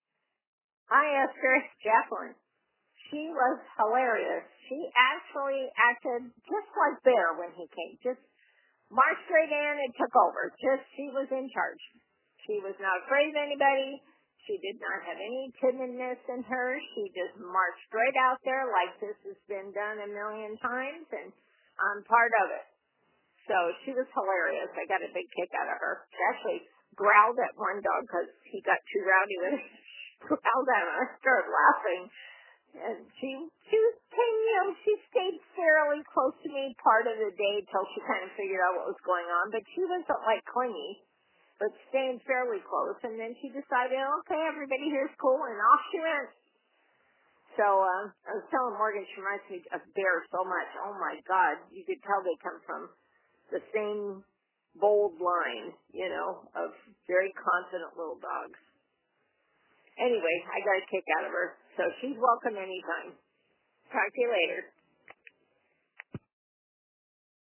trainer_testimonial.mp3